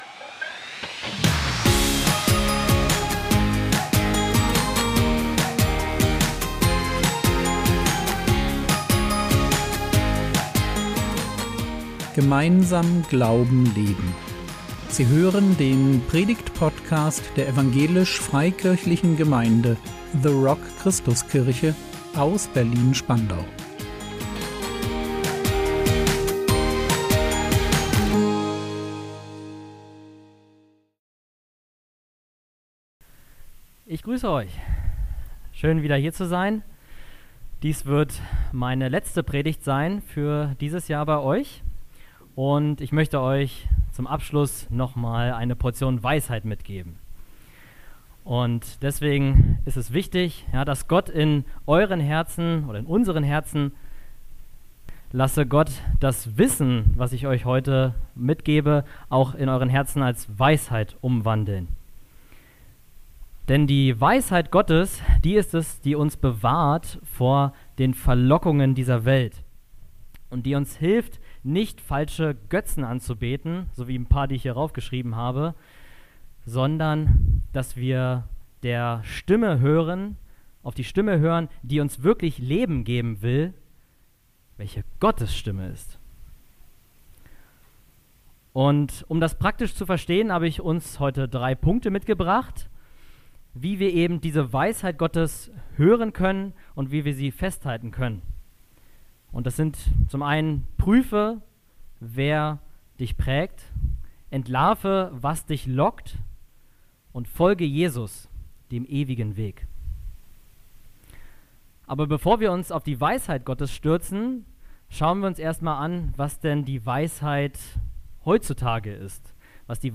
Moderne Götzen | 26.10.2025 ~ Predigt Podcast der EFG The Rock Christuskirche Berlin Podcast